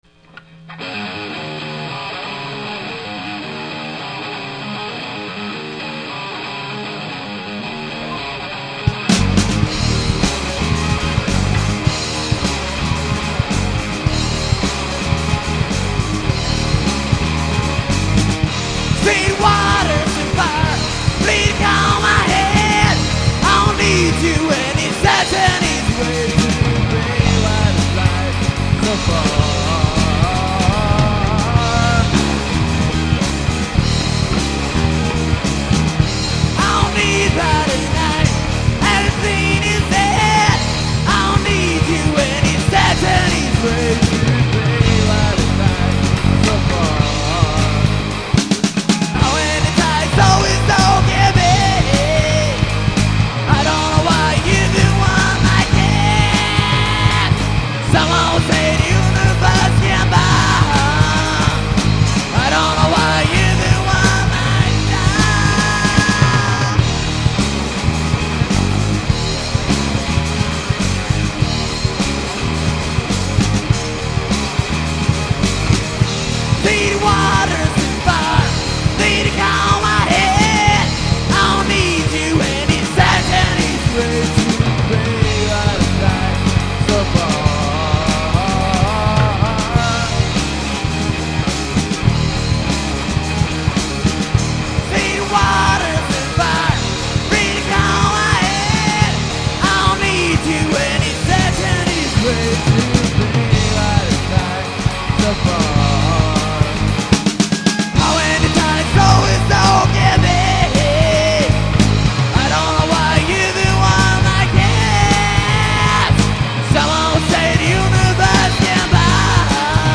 Gravada ao vivo em março de 1999 [ingles]  ) -